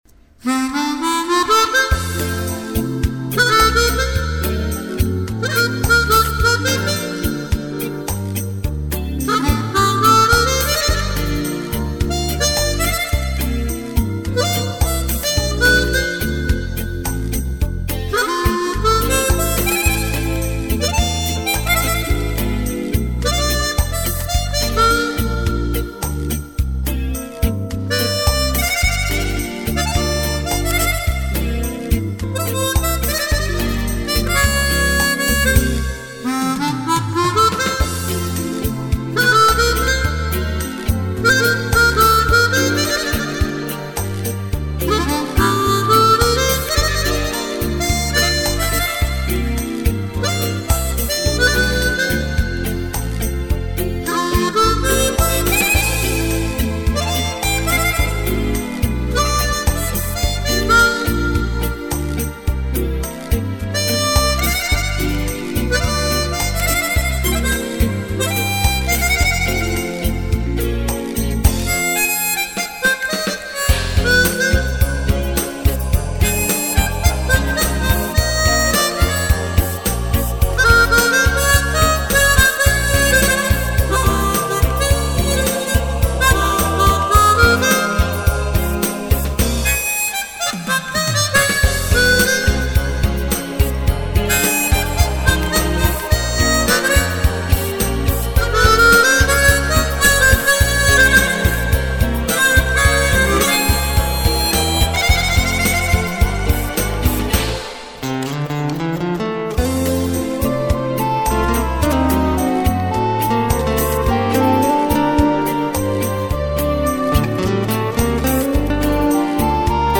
version harmonica